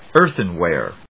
音節éarthen・wàre 発音記号・読み方
/ˈɝθʌˌnwɛr(米国英語), ˈɜ:θʌˌnwer(英国英語)/